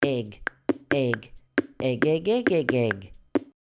Ringtone.